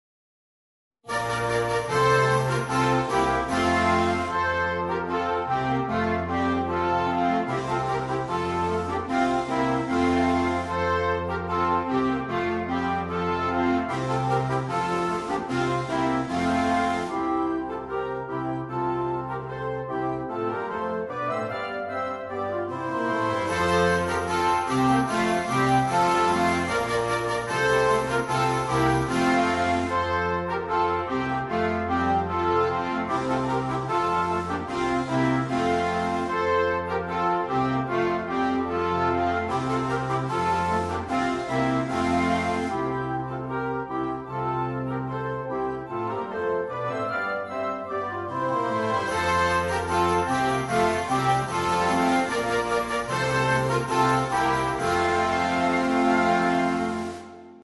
Per banda